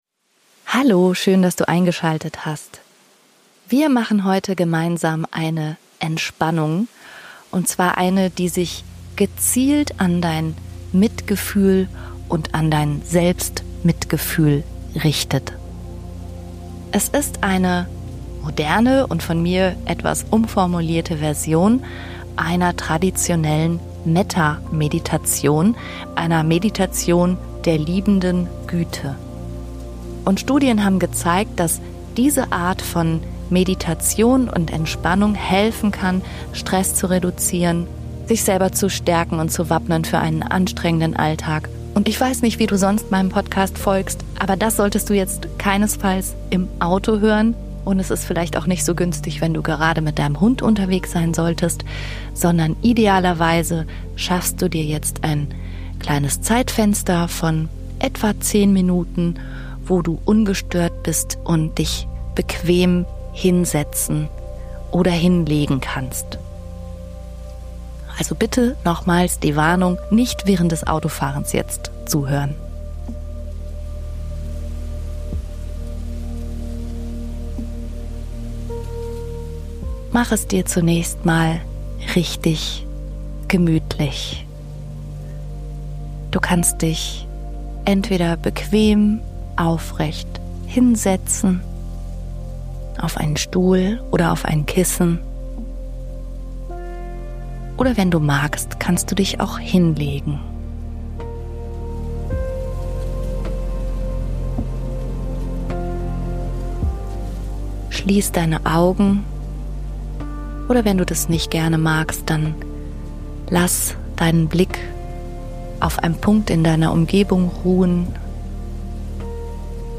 Eine geführte Entspannung für mehr (Selbst-) Mitgefühl, Freundlichkeit und Güte.